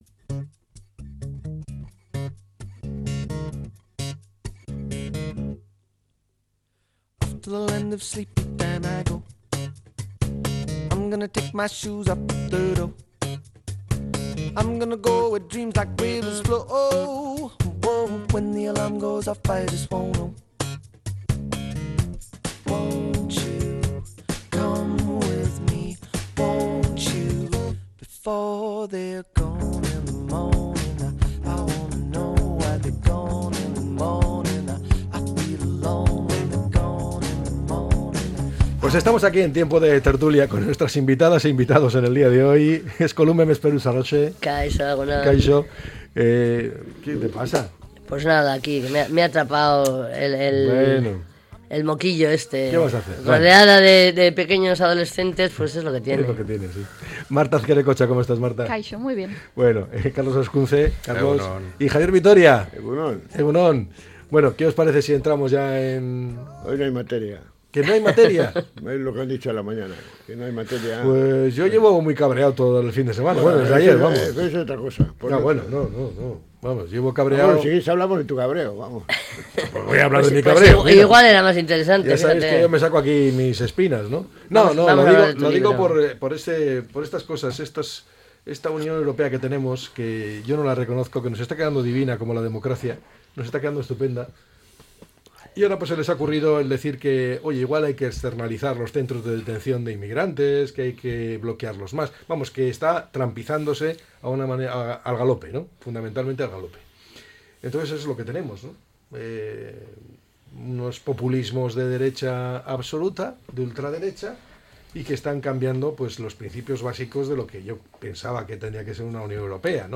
analiza a diario diferentes temas de actualidad con sus tertulianxs